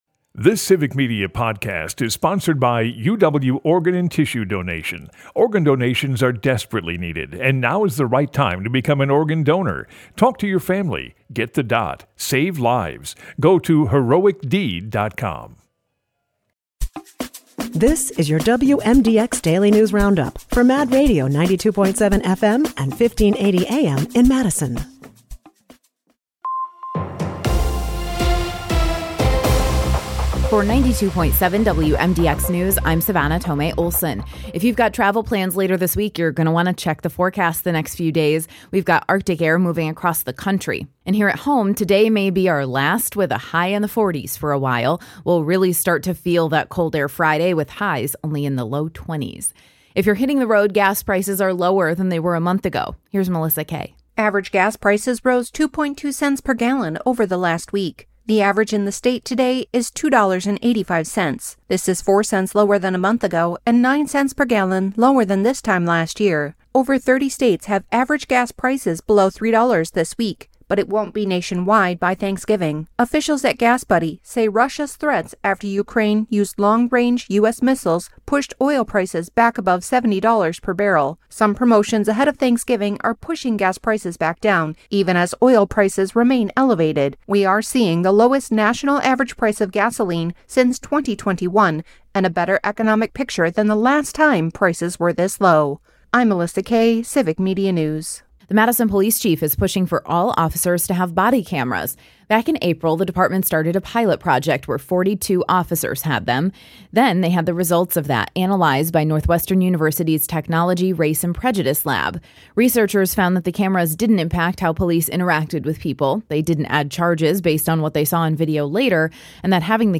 The WMDX Mad Radio Daily News Roundup has your state and local news, weather, and sports for Madison, delivered as a podcast every weekday at 9 a.m. Stay on top of your local news and tune in to your community!